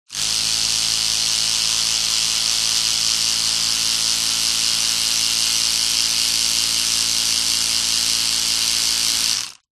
Звуки электрической зубной щетки
Шум работающего механизма зубной щетки без чистки зубов